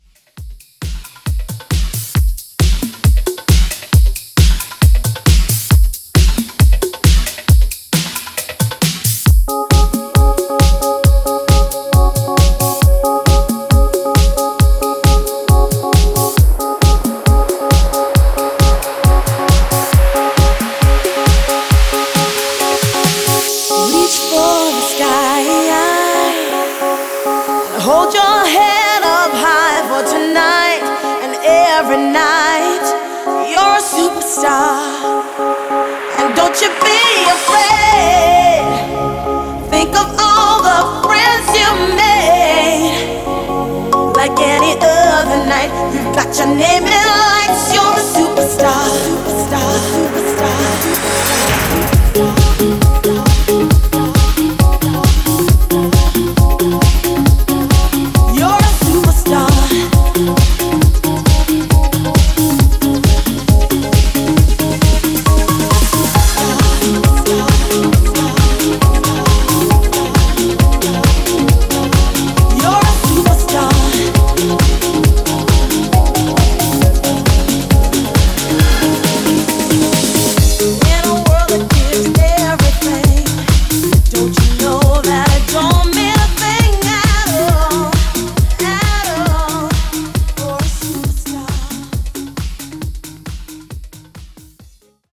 Extended Mix)Date Added